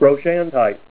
Help on Name Pronunciation: Name Pronunciation: Brochantite + Pronunciation
Say BROCHANTITE Help on Synonym: Synonym: Blanchardite   ICSD 64688   PDF 43-1488